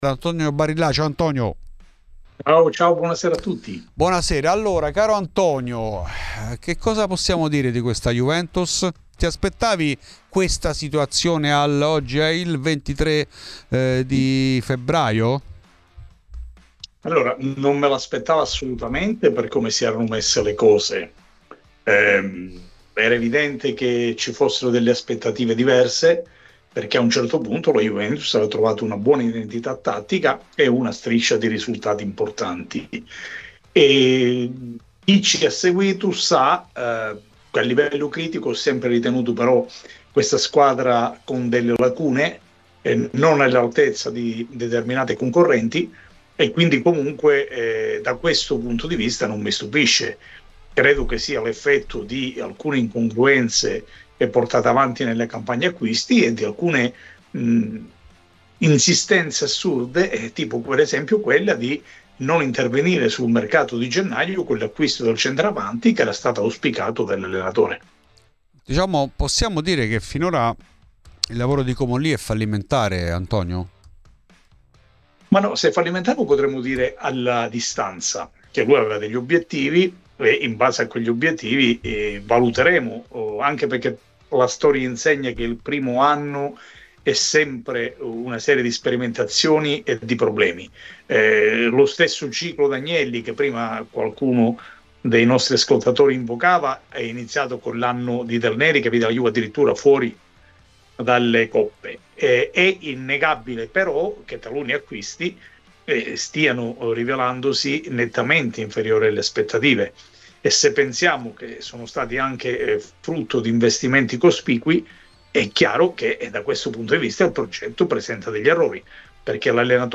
ospite di Fuori di Juve , trasmissione di Radio Bianconera .